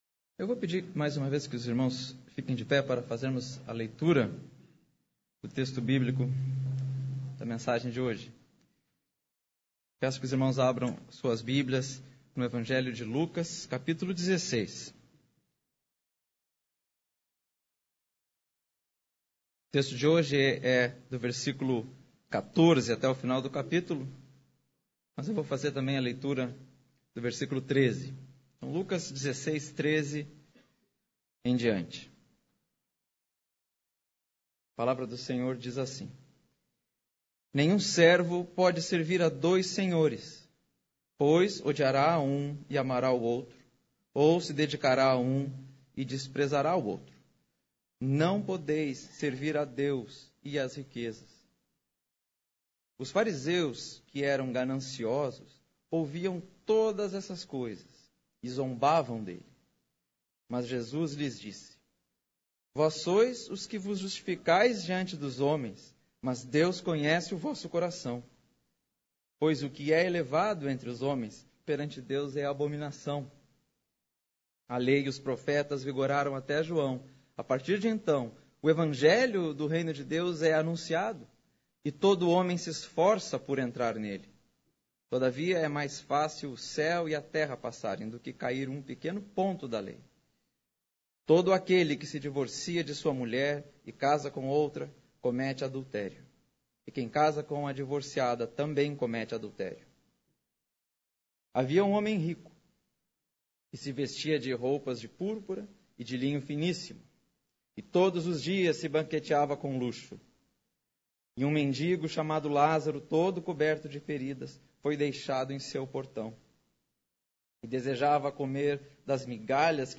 Pregação 09/03/2015 – Lucas 16:13-31